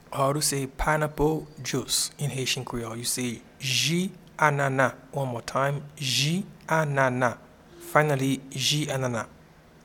Pineapple-Juice-in-Haitian-Creole-Ji-anana.mp3